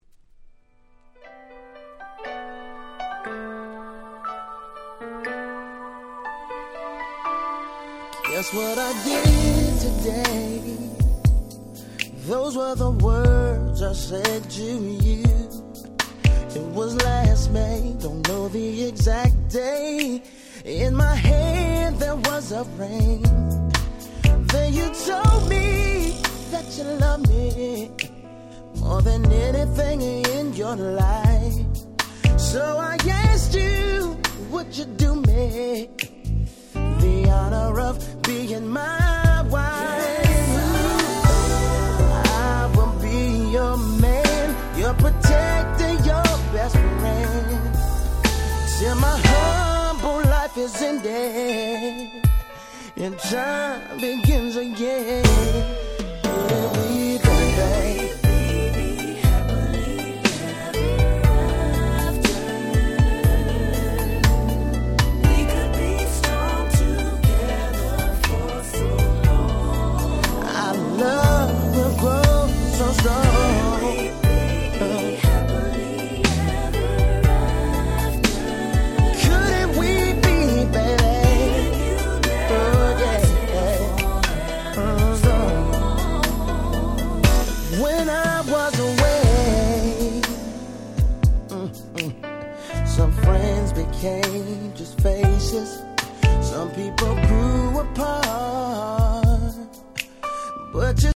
99' Super Hit R&B / Slow Jam !!
最高、最強のバラードです！！